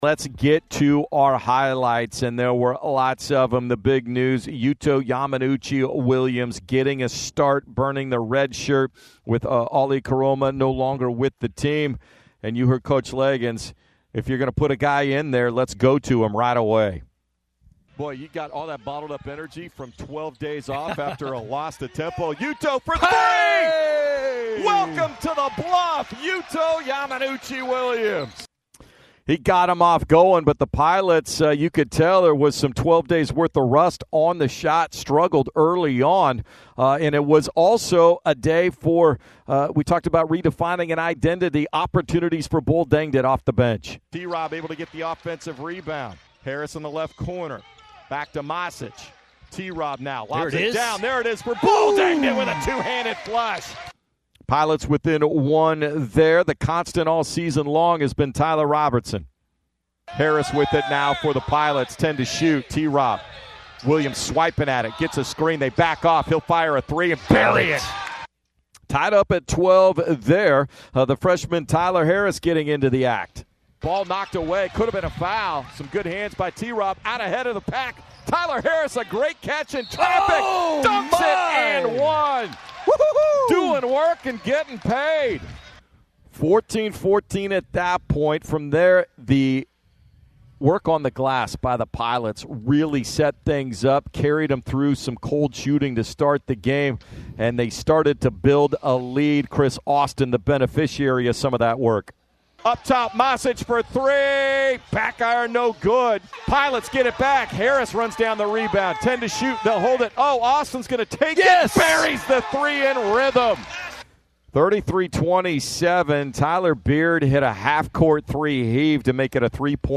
Radio Highlights vs. Pacific